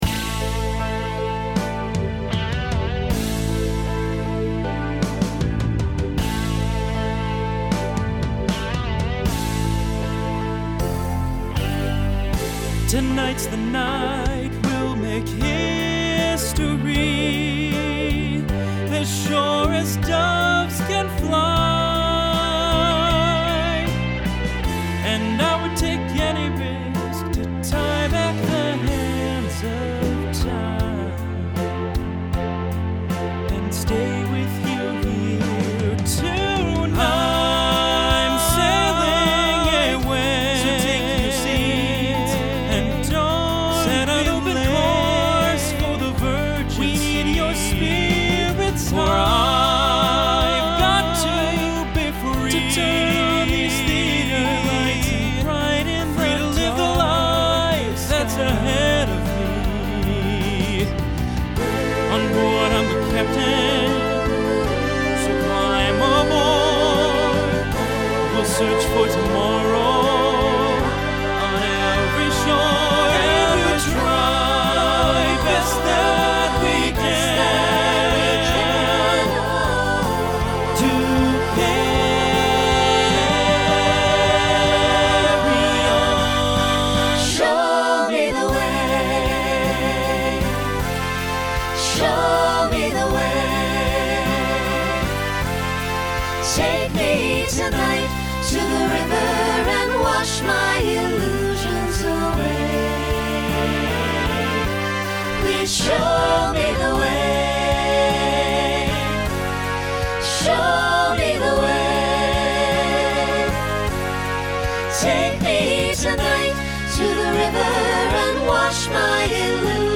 Genre Rock Instrumental combo
Transition Voicing Mixed